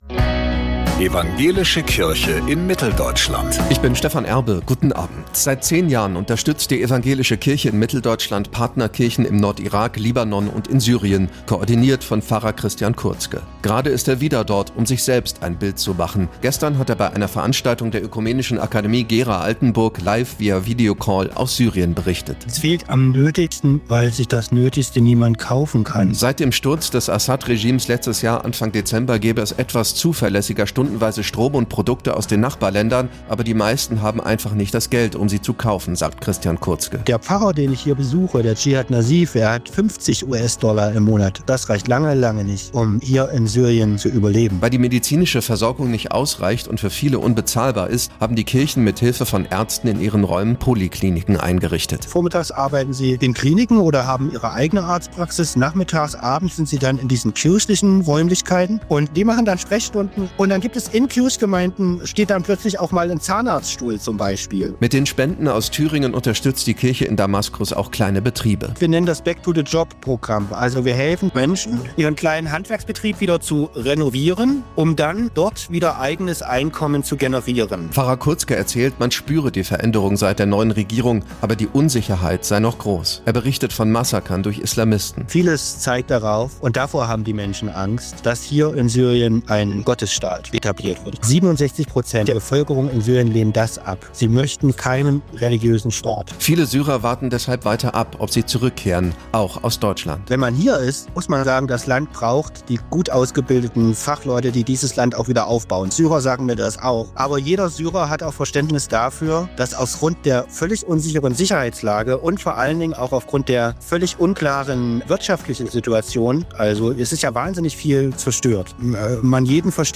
Gestern hat er bei einer Veranstaltung der Ökumenischen Akademie Gera/Altenburg live via Videocall aus Syrien berichtet.